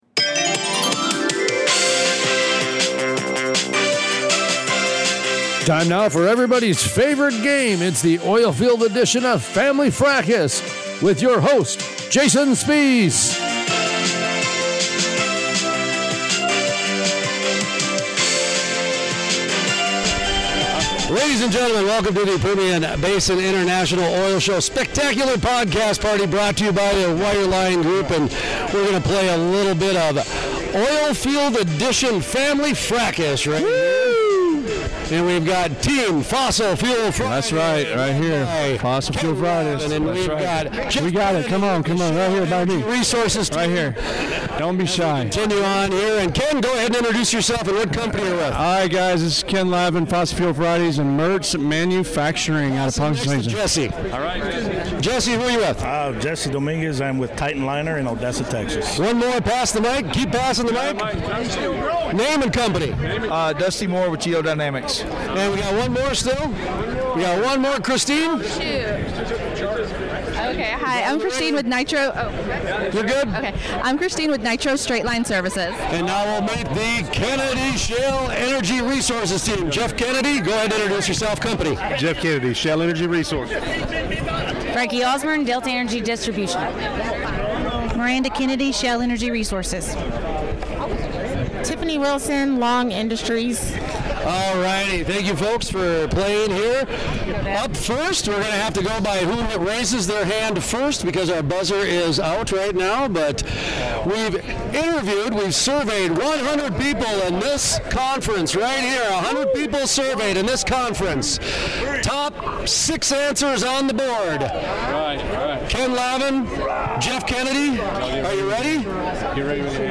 The show debuted at the Permian Basin International Oil Show (PBIOS) sponsored by The Wireline Group in Odessa, TX.